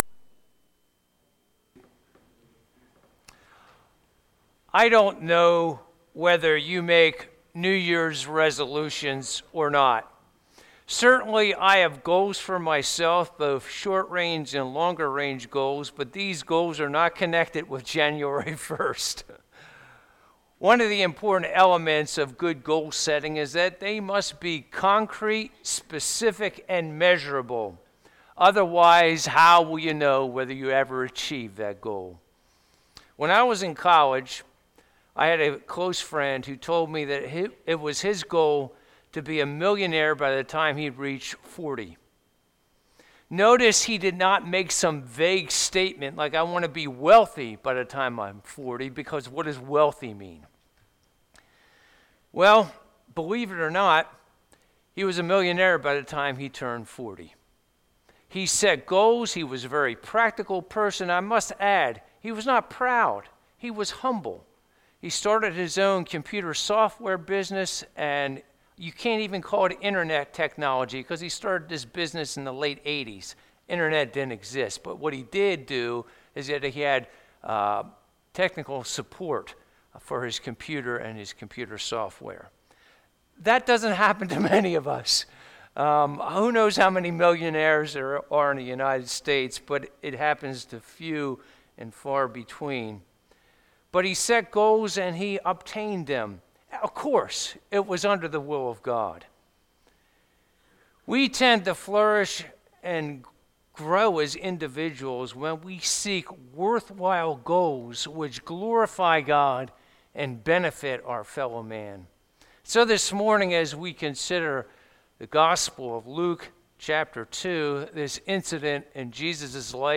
Bible Text: Luke 2:41-52 | Preacher